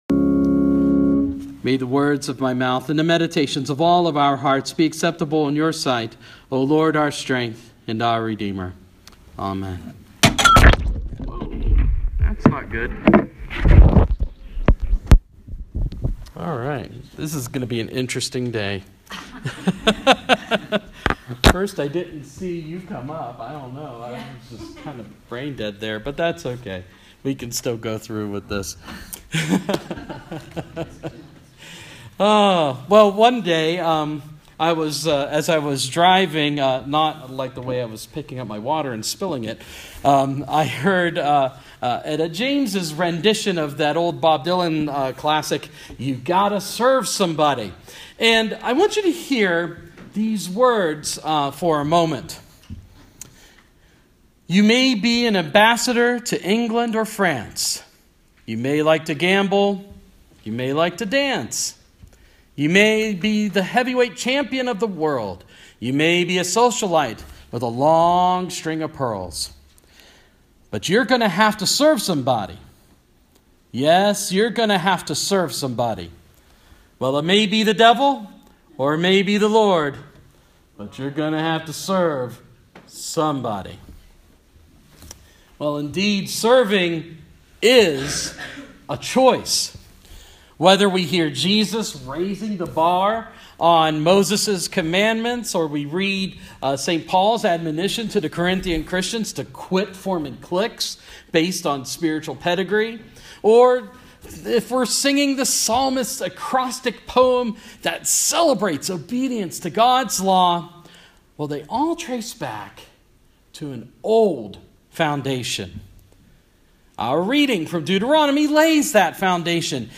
(Please excuse the water spill at the beginning of the recording.)